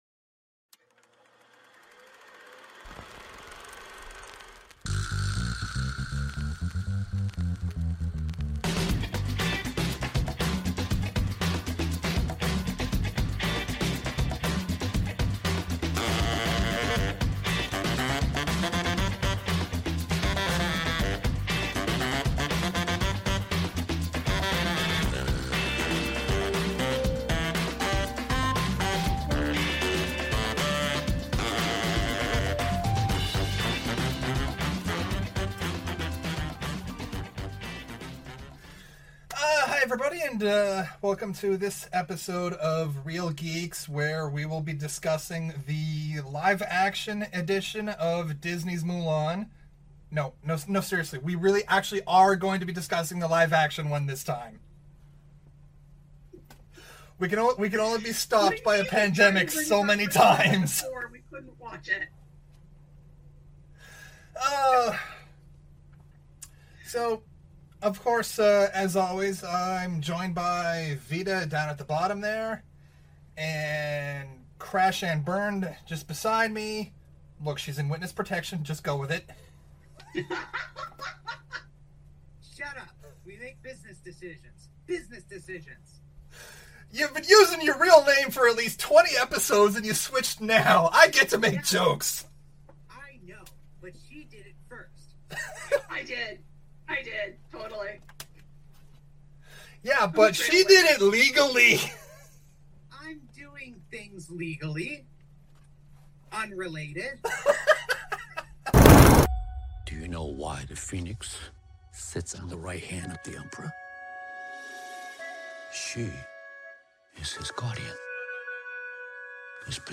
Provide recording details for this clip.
Originally recorded in Halifax, NS, Canada Video: Whereby Video Conferencing